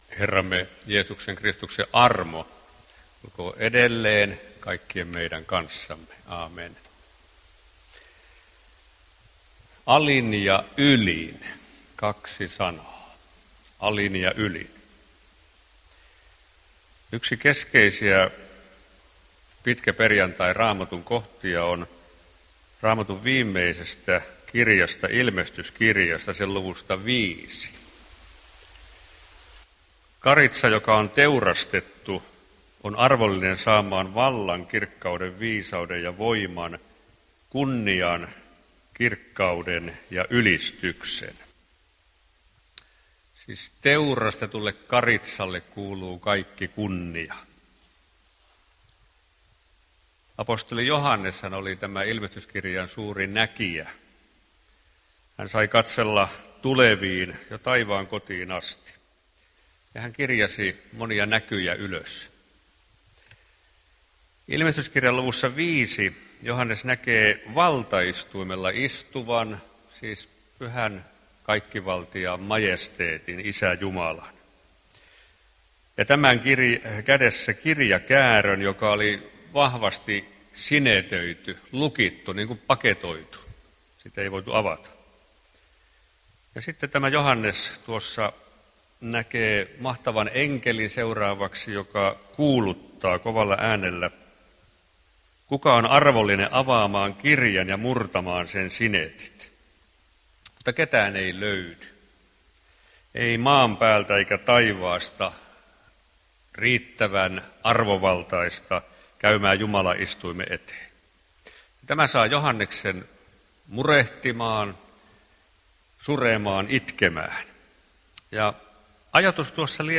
pitkäperjantain ristinjuhlassa Tekstinä Ilm. 5: 12